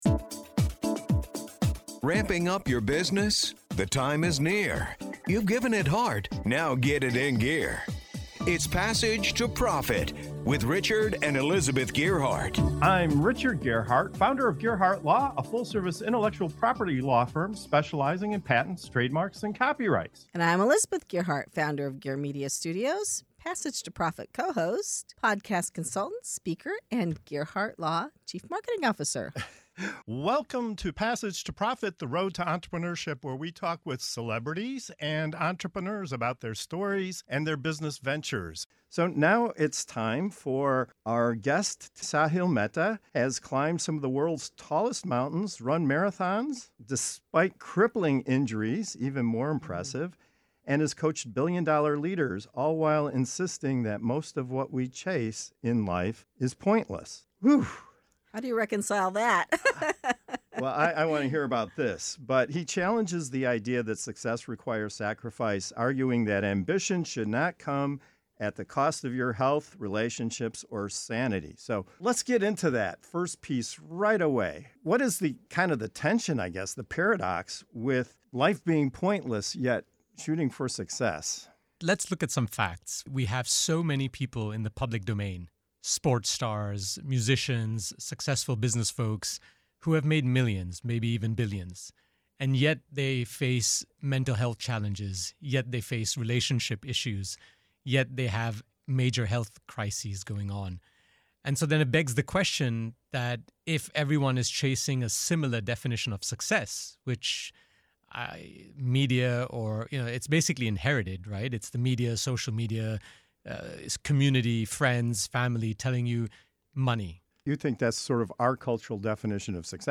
co-hosts of Passage to Profit Show interview entrepreneur